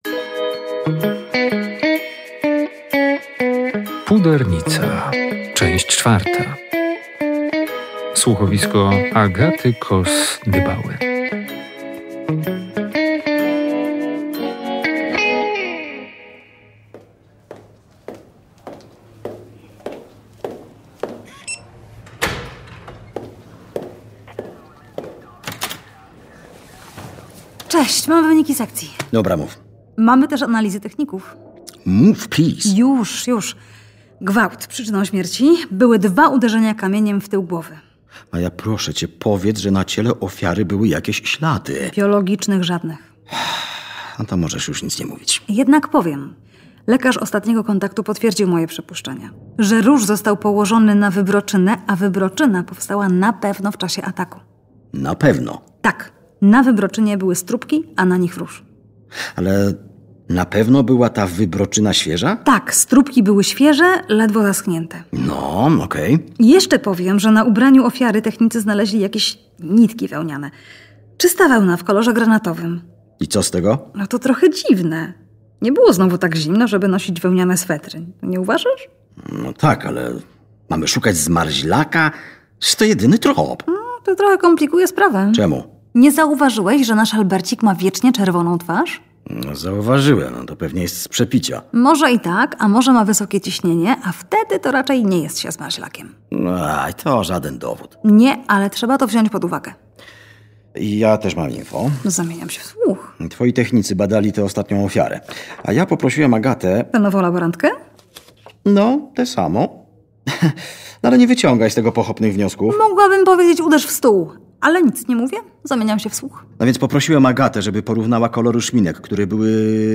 Ostatnie spotkanie z bohaterami słuchowiska przyniesie odpowiedź na pytanie, kim jest seryjny zabójca grasujący po bardzo specyficznej dzielnicy i kim jest pudernica.